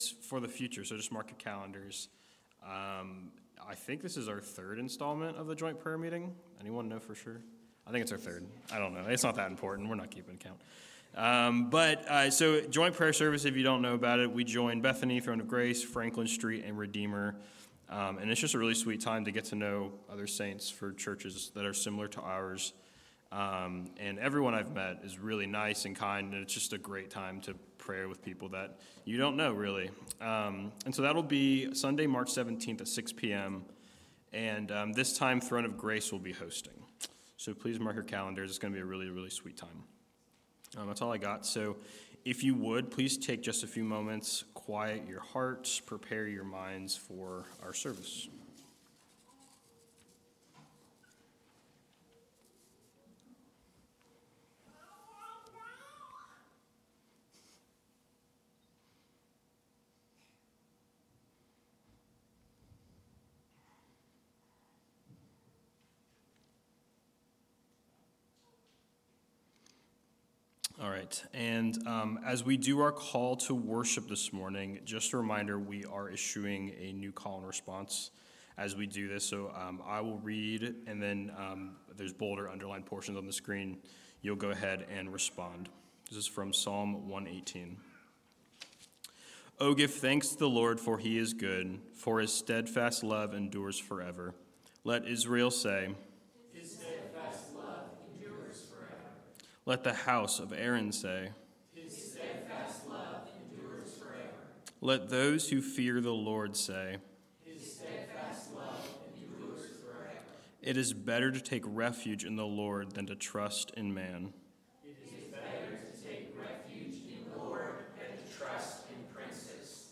January 14 Worship Audio – Full Service